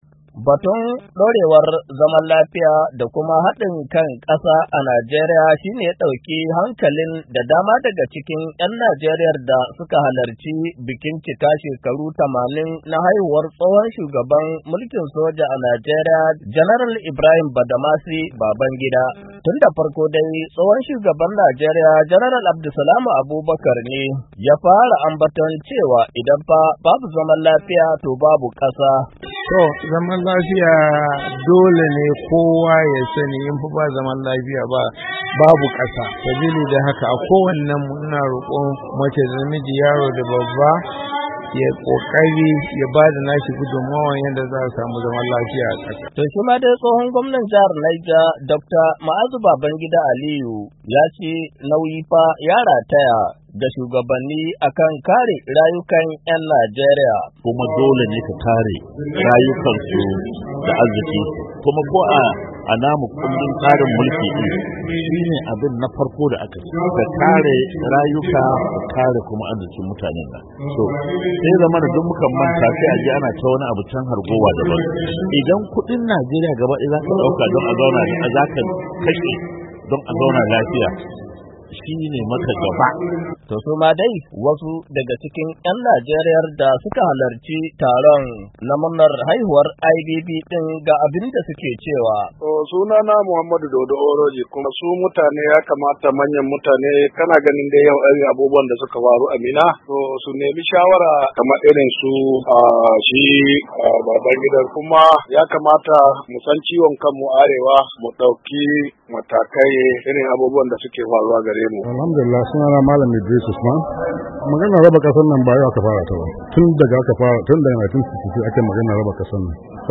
Saurari rahoto cikin sauti